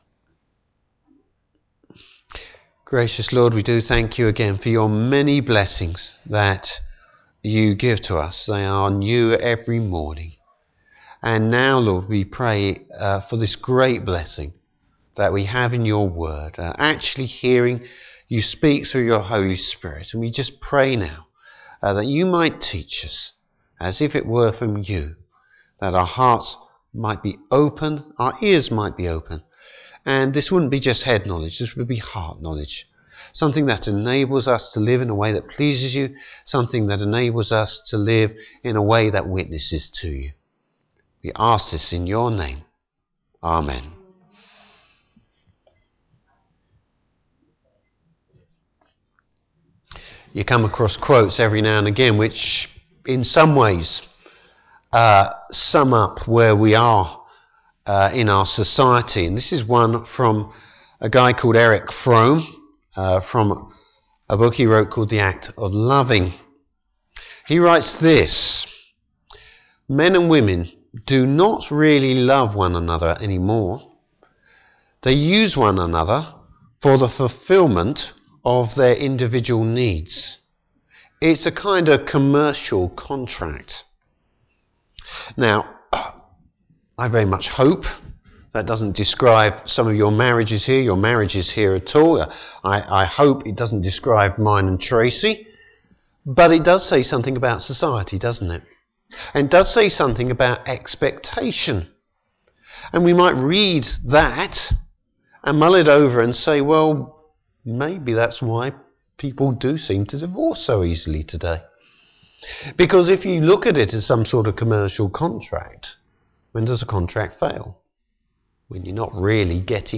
Service Type: Morning Service Bible Text: Ruth 4.